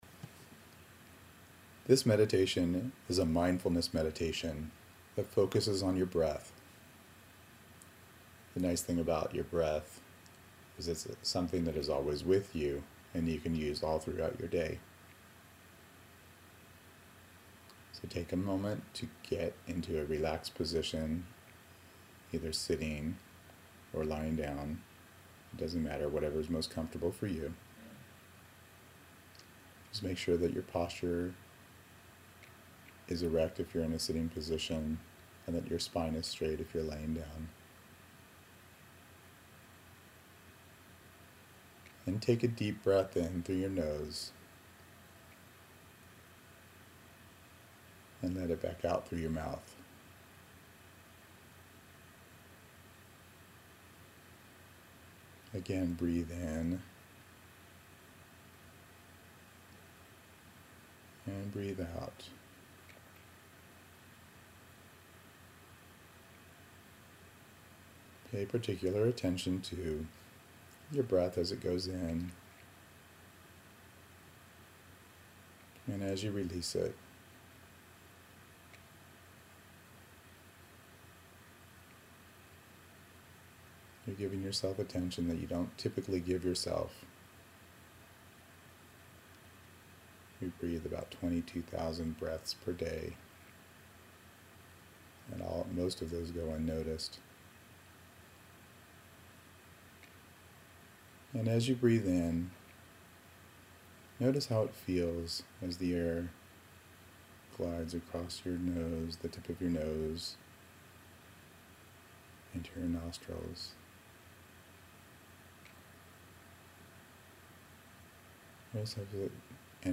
Mindfulness Breathing Meditation